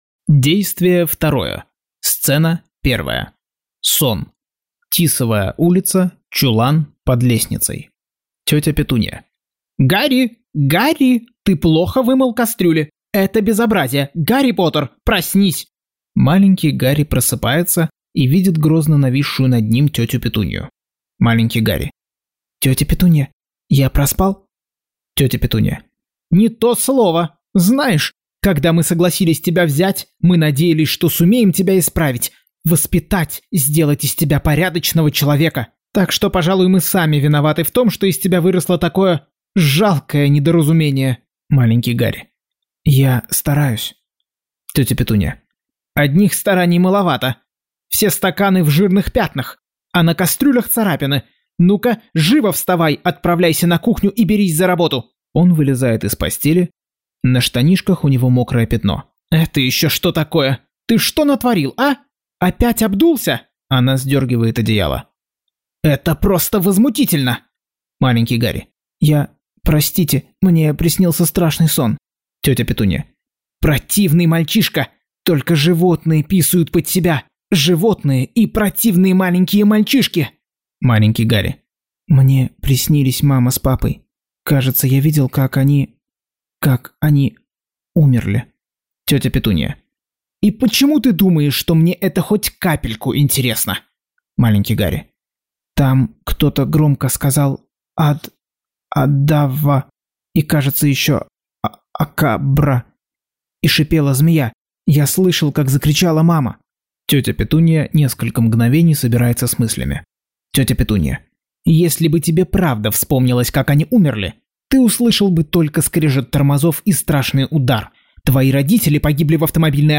Аудиокнига Гарри Поттер и проклятое дитя. Часть 1.